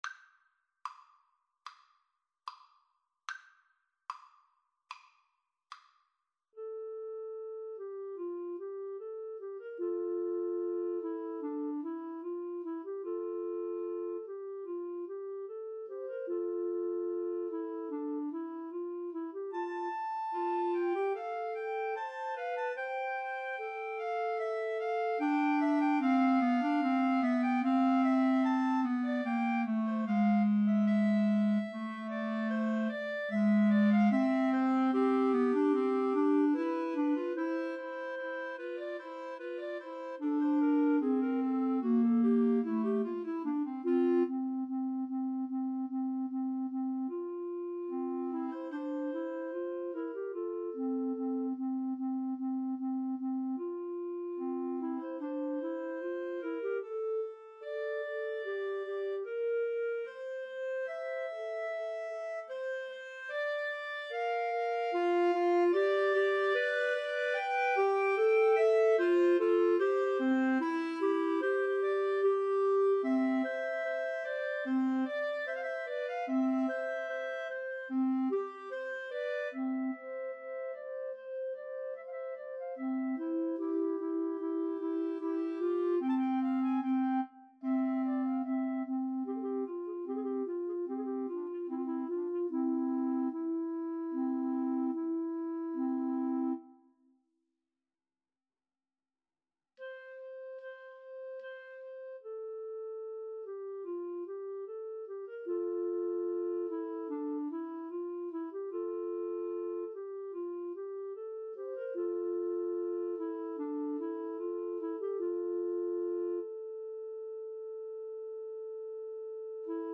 ~ = 74 Moderato
Clarinet Trio  (View more Intermediate Clarinet Trio Music)
Classical (View more Classical Clarinet Trio Music)